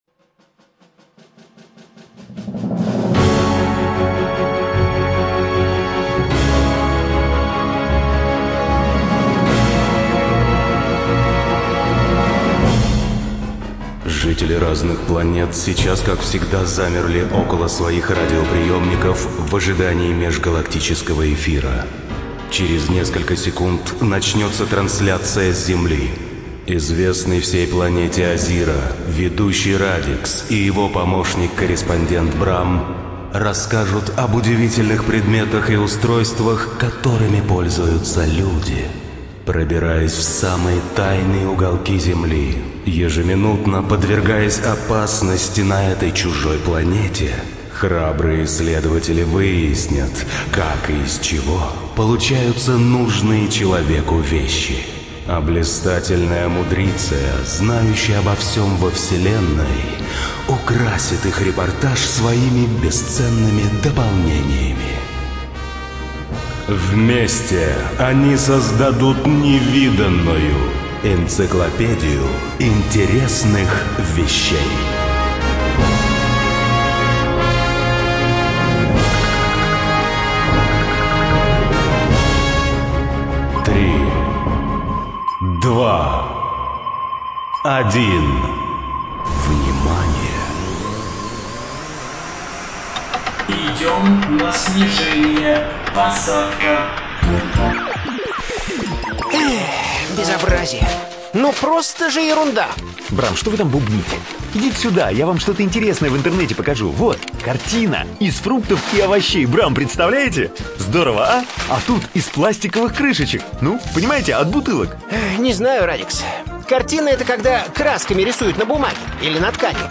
НазваниеЭнциклопедия интересных вещей. Цикл радиопередач
ЧитаетДетское радио
Студия звукозаписиДетское радио